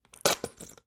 На этой странице собраны звуки кетчупа, майонеза и других соусов — от хлюпающих до булькающих.
Звуки кетчупа, майонеза, соусов: как звучит майонез при выдавливании из пакета